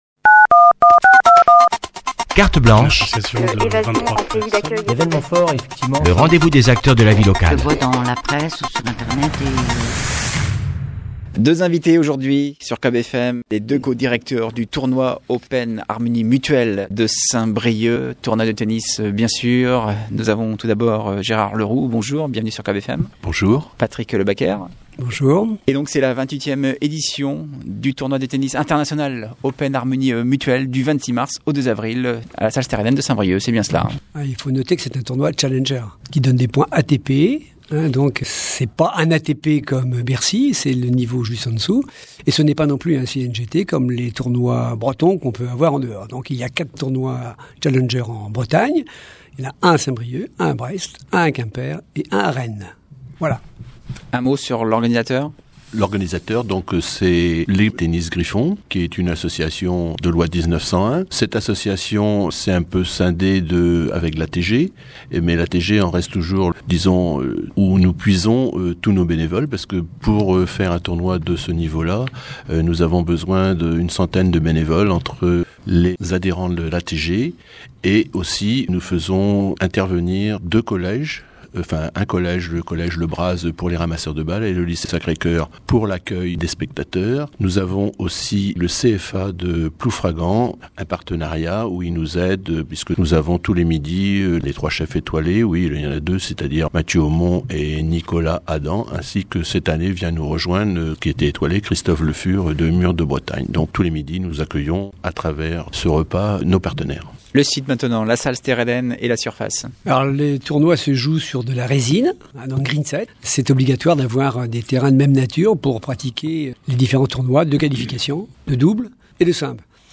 Au micro de COB’FM